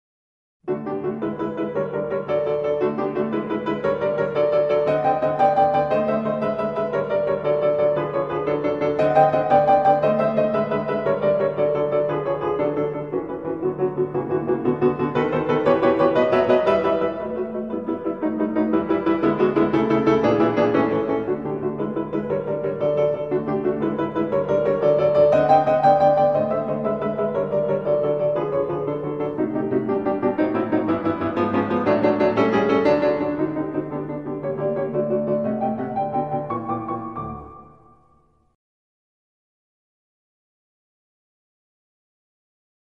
фортепианная версия